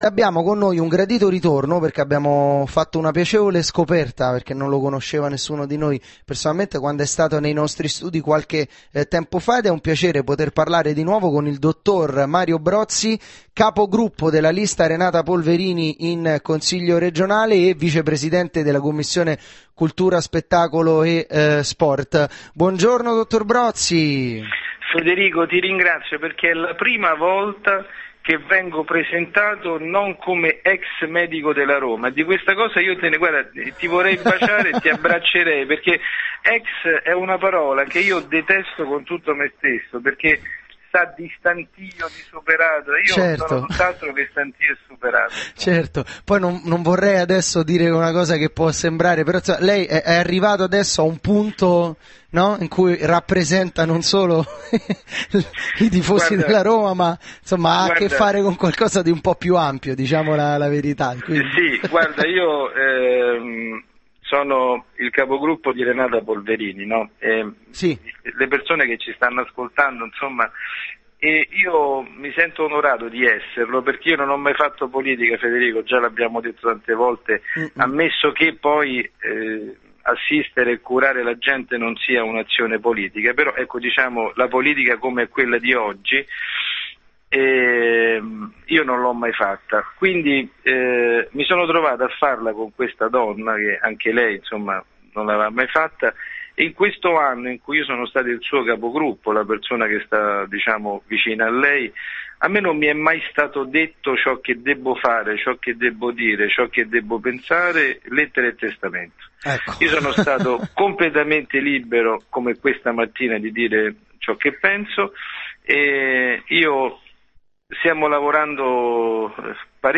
programma radiofonico